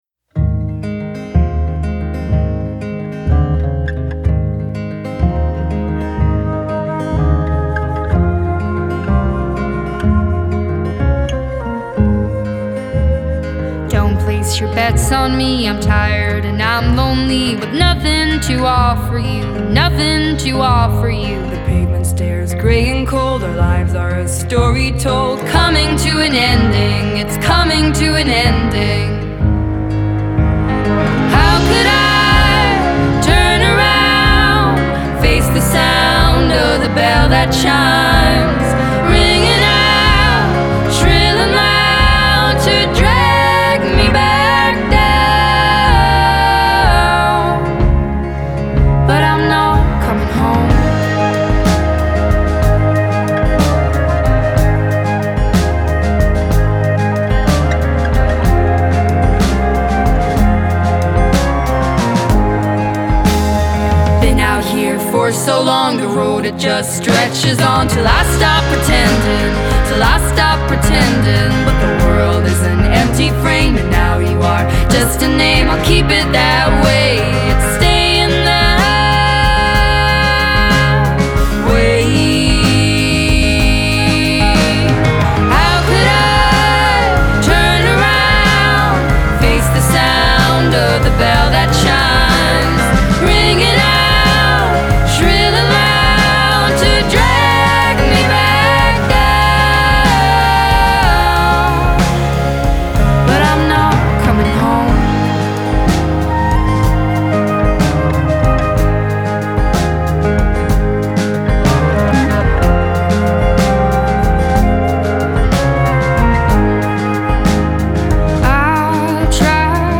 Genre: Indie Pop, Indie Folk